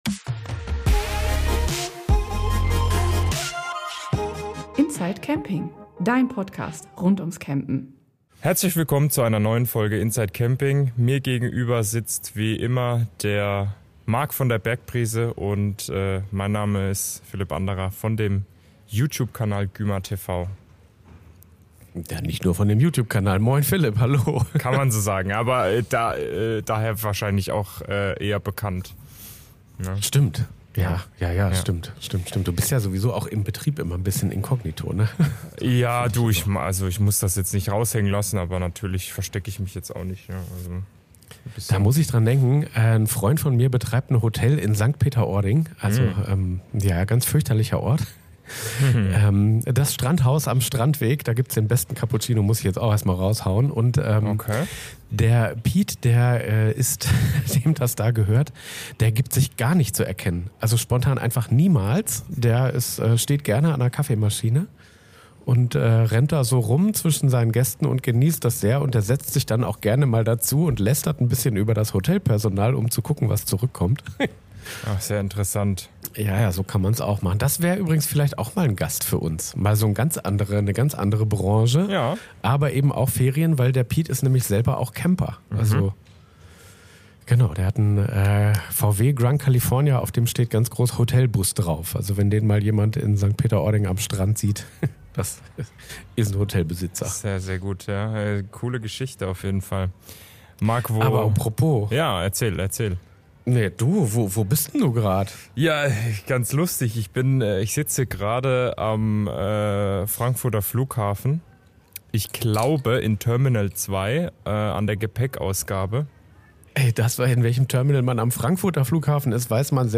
meldet sich von Terminal 2 im Frankfurter Flughafen